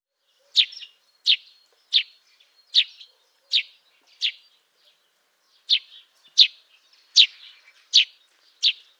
Der Haussperling ruft oft ein lautes, etwas raues tschilp, während der Feldsperling ein weicheres, melodischeres tschip von sich gibt.
Feldsperling Gesang
Feldsperling-Gesang-Voegel-in-Europa.wav